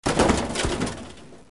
Bidone rotolante
Bidone o latta metallica che rotola in stereo.
Effetto sonoro - Bidone rotolante